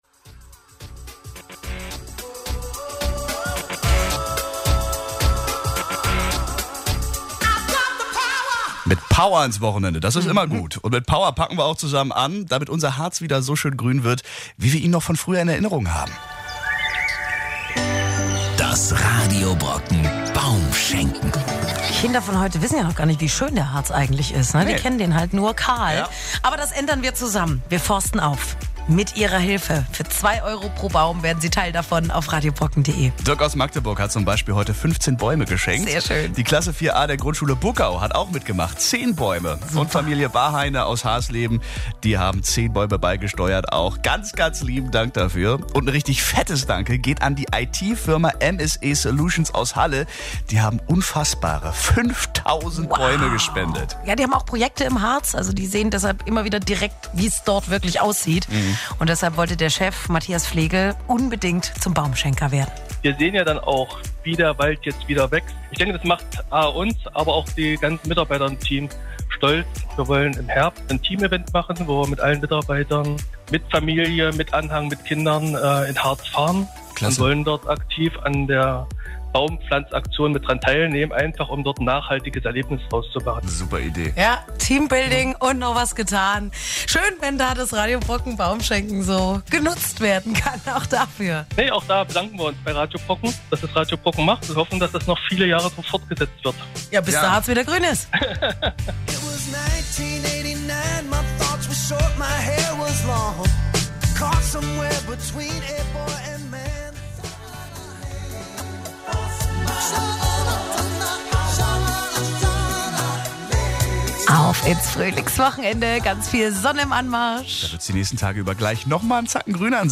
Interviewmitschnitt über unsere Spende an die Radio Brocken Baum-Schenken-Aktion - 5.000 Bäume für den Harz
Interview mit Radio Brocken